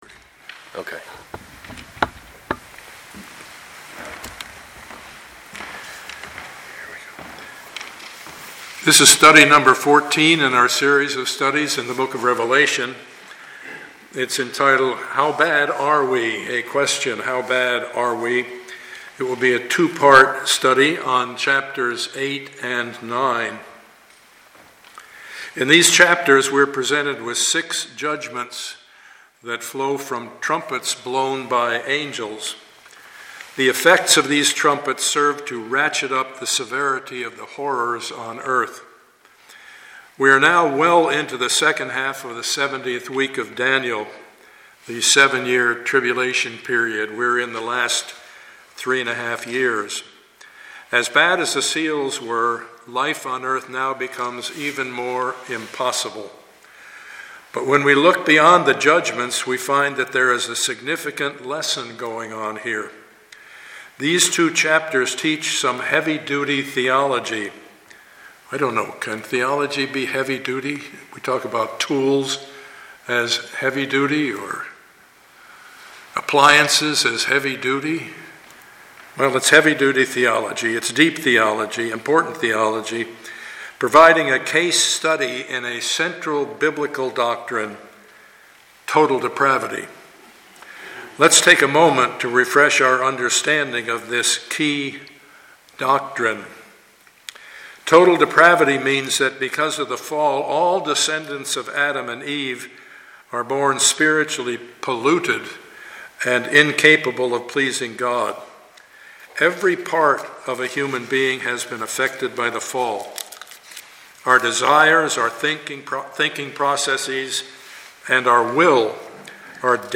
Passage: Revelation 8-9 Service Type: Sunday morning